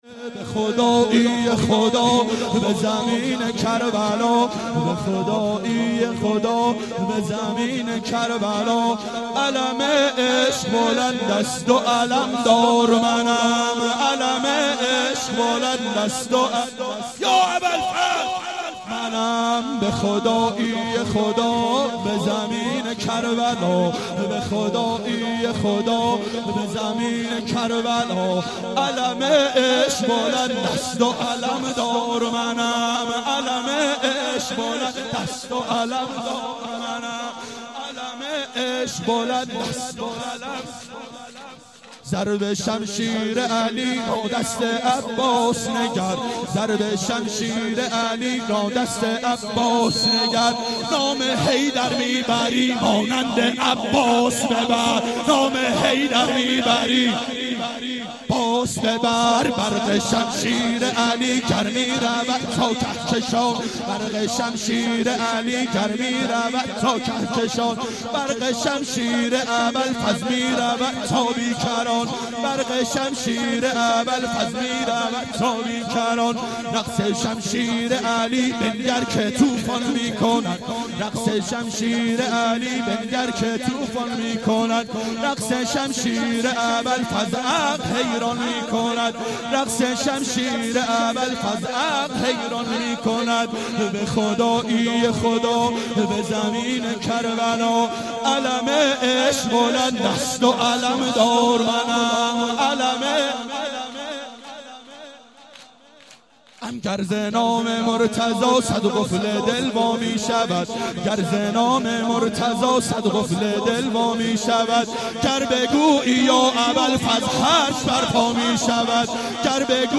دهه اول صفر سال 1391 هیئت شیفتگان حضرت رقیه سلام الله علیها (شام غریبان)